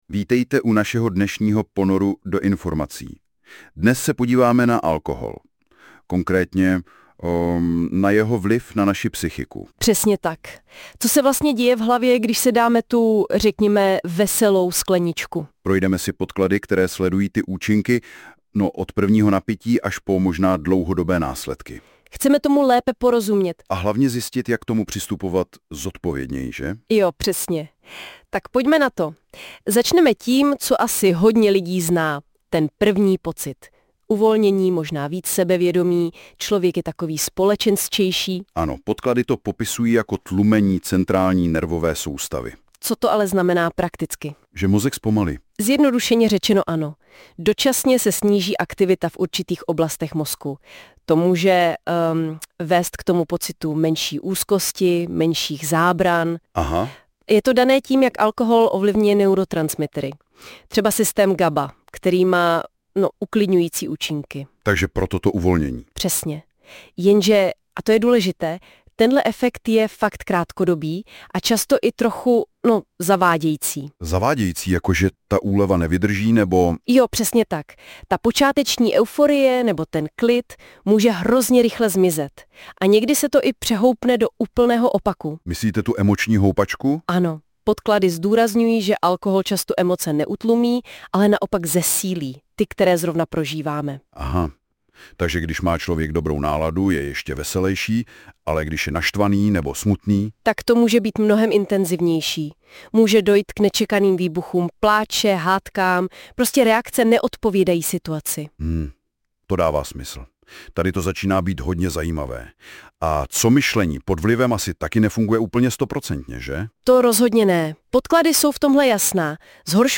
Audioverze vytvořená na základě tohoto článku pomocí nástroje NotebookLM.